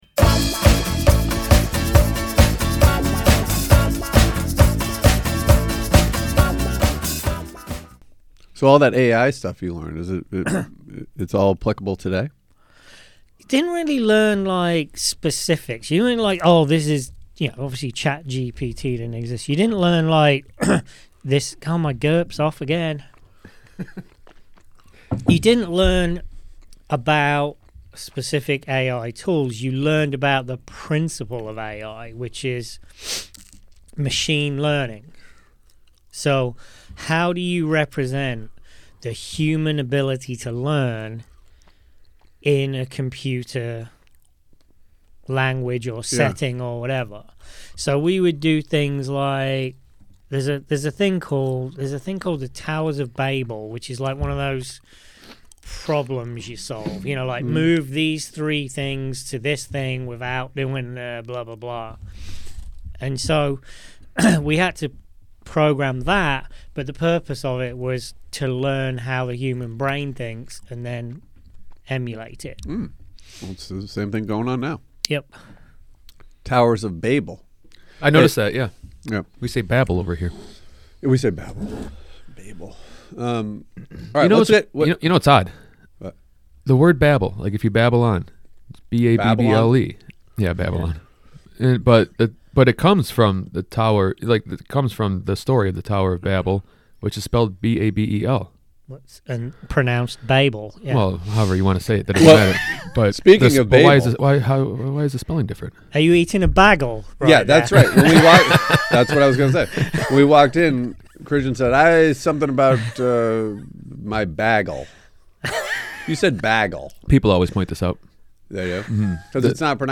Review: The Comeback Kid, John Mulaney